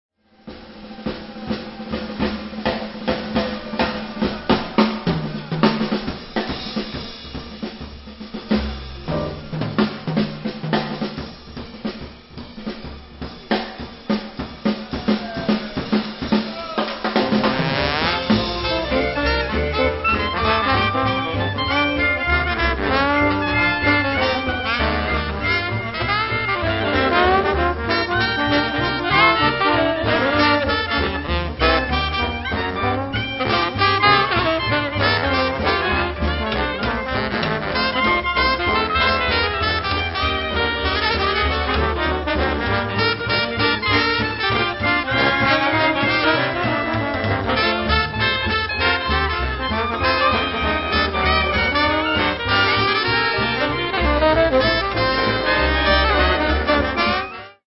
cornetta
trombone
clarinetto
pianoforte
contrabbasso
batteria